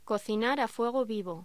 Locución: Cocinar a fuego vivo
voz